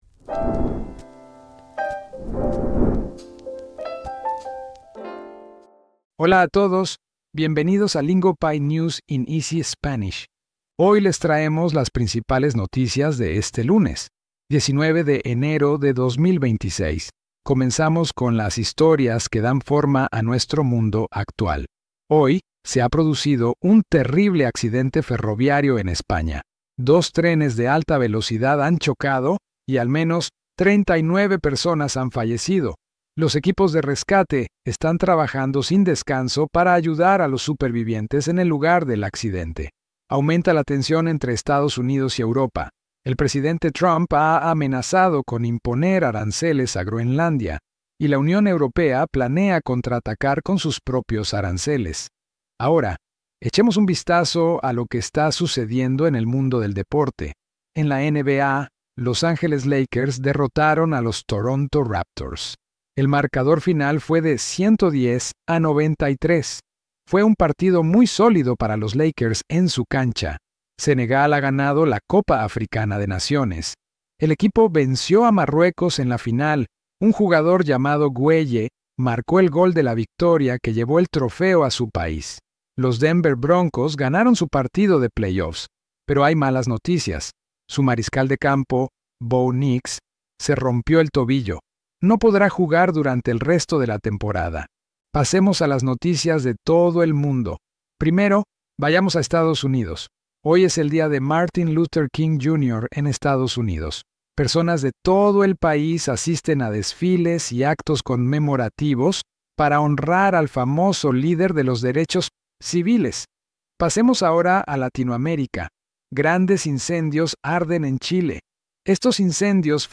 Lingopie’s News in Easy Spanish turns complex global events into clear, structured Spanish.